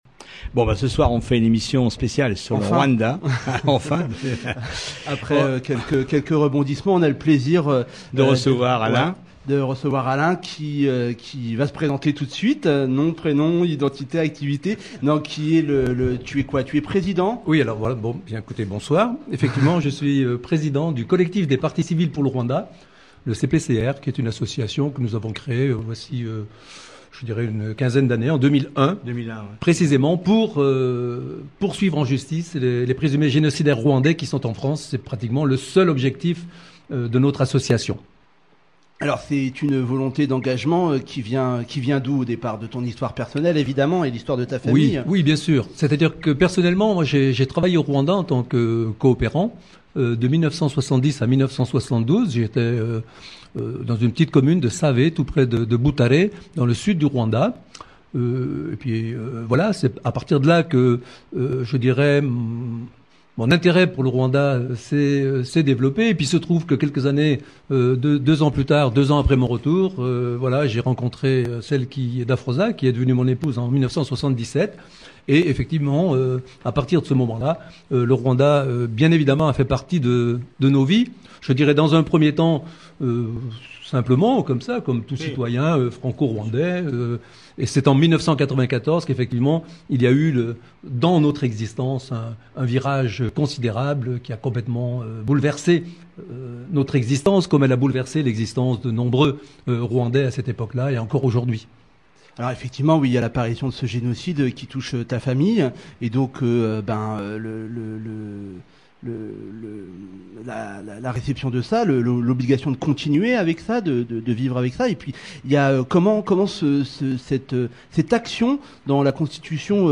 échange de manière claire et vivante avec les journalistes de Radio Primitive à Reims